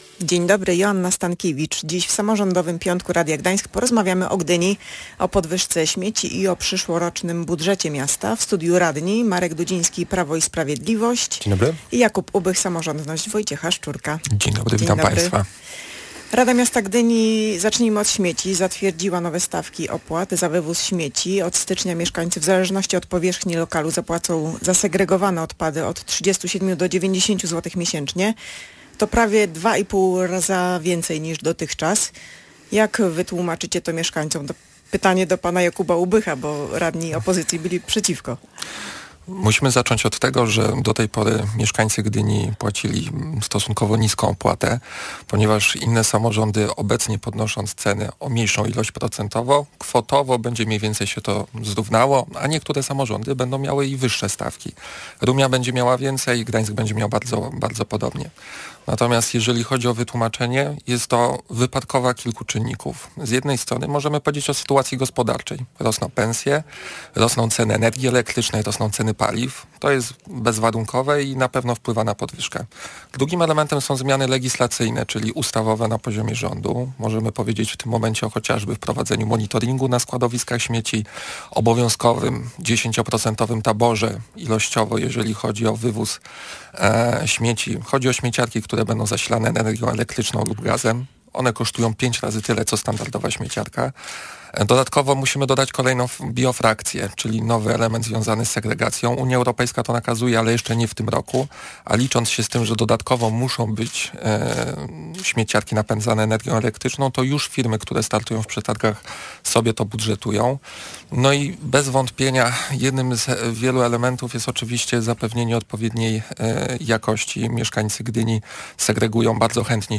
w audycji Samorządowy piątek byli gdyńscy radni: Marek Dudziński z Prawa i Sprawiedliwości i Jakub Ubych z „Samorządności” Wojciecha Szczurka.